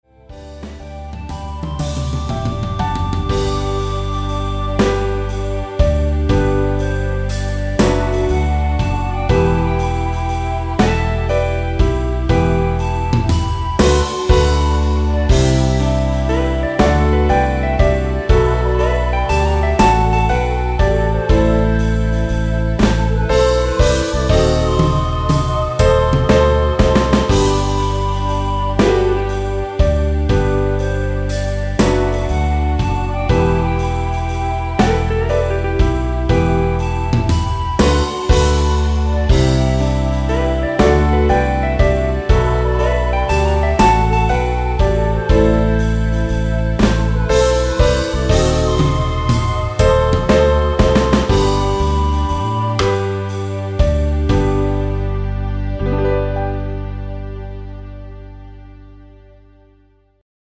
엔딩이 페이드 아웃이라 가사 끝 (널 사랑해) 까지 하고 엔딩을 만들었습니다.(가사및 미리듣기 참조)
음정과 박자 맞추기가 쉬워서 노래방 처럼 노래 부분에 가이드 멜로디가 포함된걸
앞부분30초, 뒷부분30초씩 편집해서 올려 드리고 있습니다.
중간에 음이 끈어지고 다시 나오는 이유는